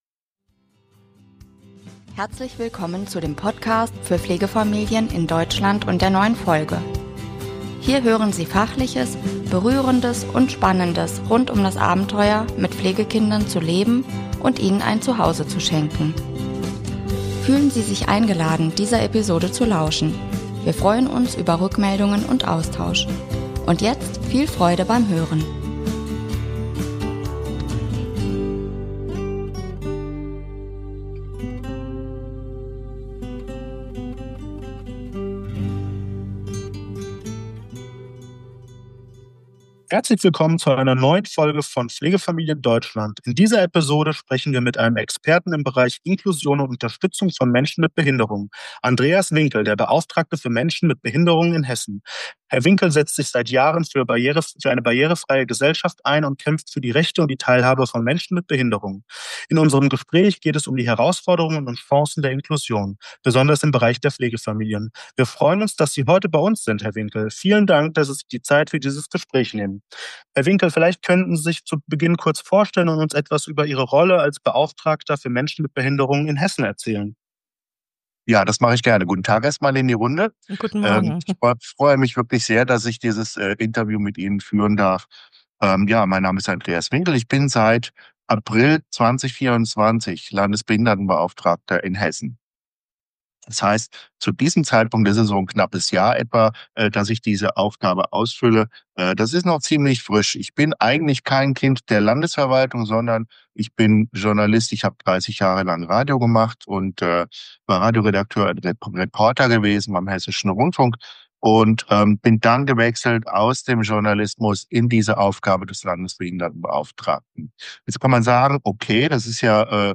In dieser Episode sprechen wir mit einem Experten im Bereich Inklusion und Unterstützung von Menschen mit Behinderungen: Andreas Winkel, der hessische Landesbeauftragte für Menschen mit Behinderungen.